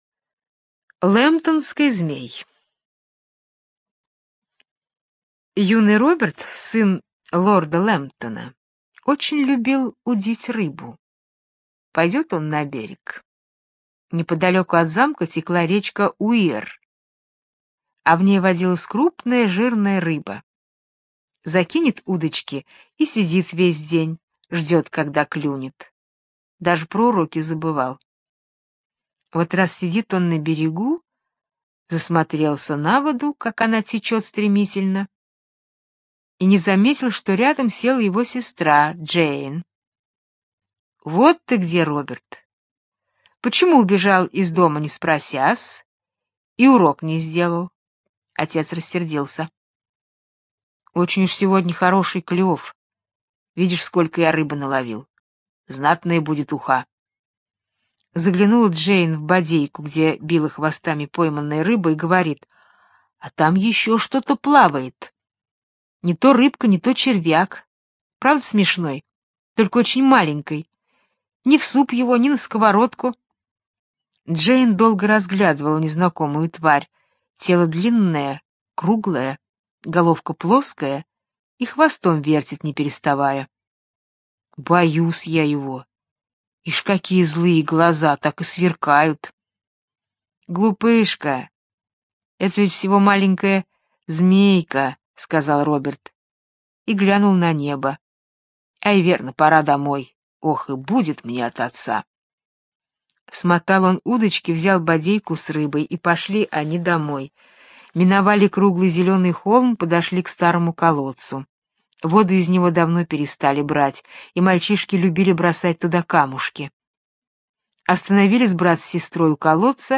Лэмбтонский Змей - британская аудиосказка - слушать онлайн